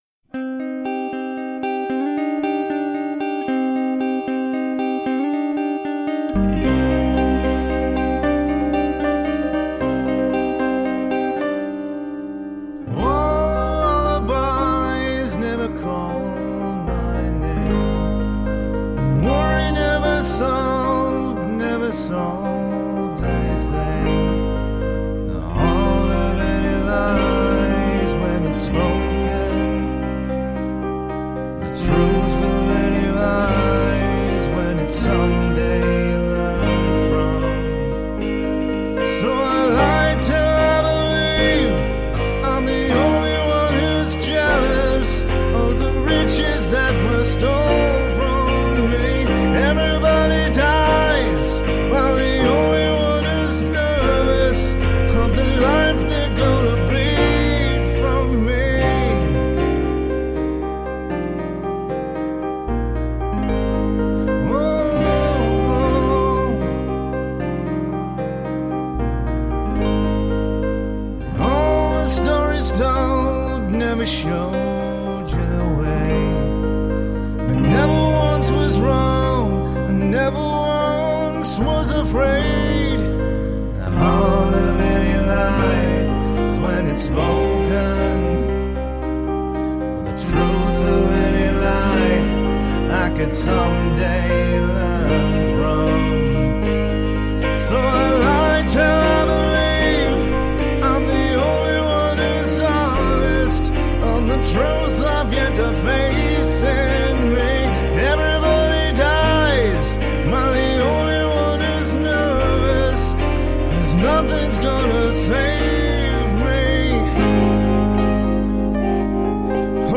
Indie rock when lyrics were king.
Tagged as: Alt Rock, Rock